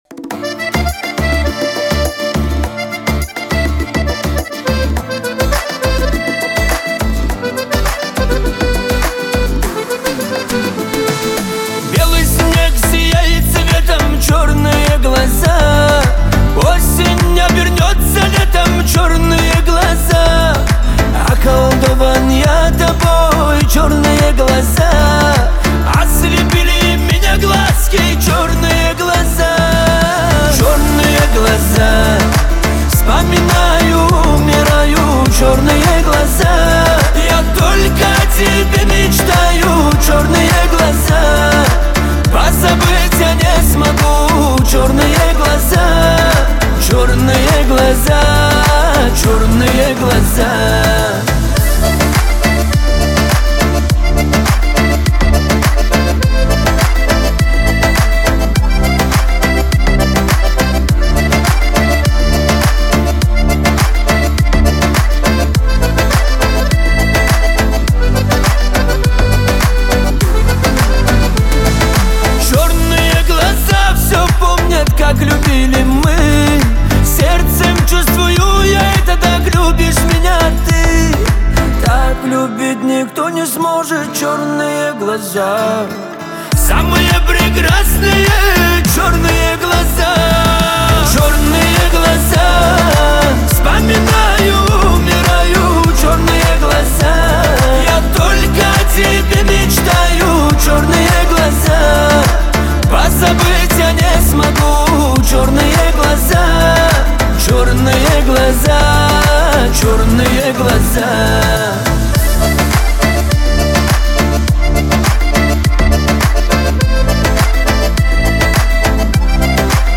шансон музыка
танцевальные песни